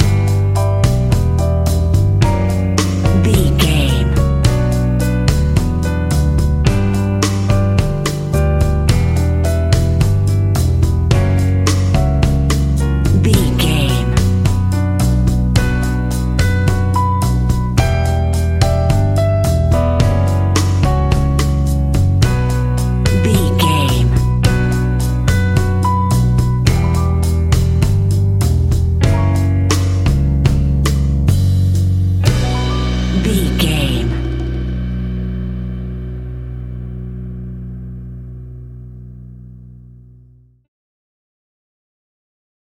An exotic and colorful piece of Espanic and Latin music.
Aeolian/Minor
Slow
flamenco
maracas
percussion spanish guitar